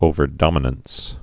(ōvər-dŏmə-nəns)